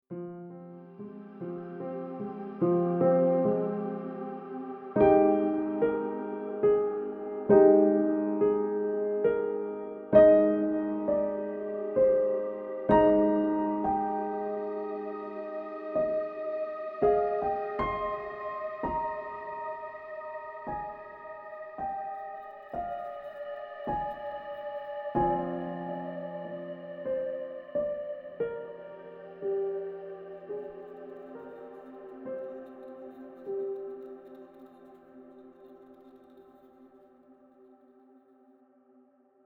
Download Romantic sound effect for free.
Romantic